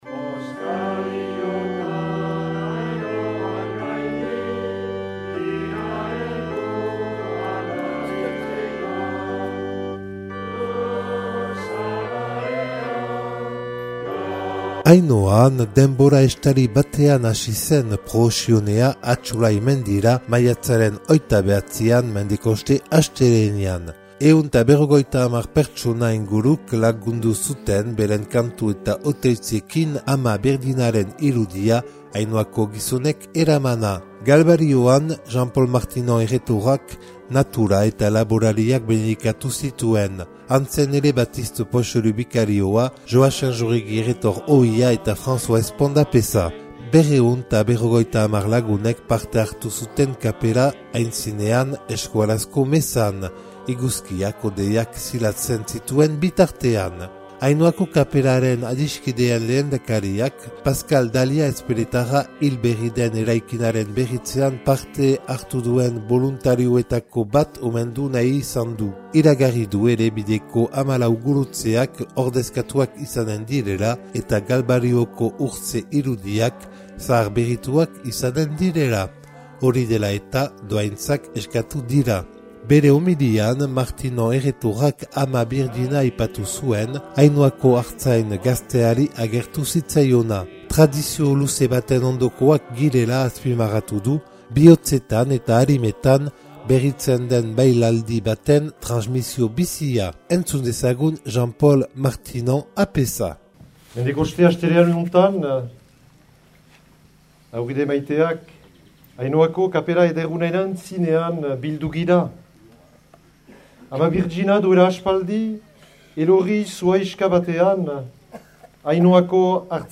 250 lagunek parte hartu zuten kapera aintzinean euskarazko mezan, iguzkiak hodeiak zilatzen zituen bitartean.